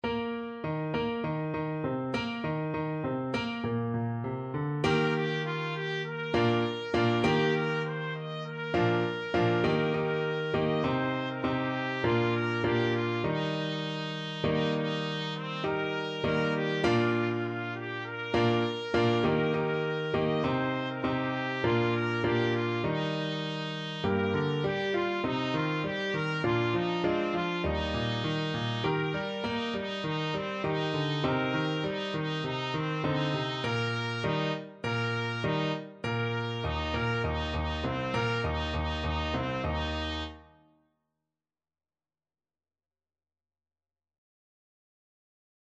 World Asia China Feng Yang
Trumpet
Traditional Music of unknown author.
4/4 (View more 4/4 Music)
Joyfully =c.100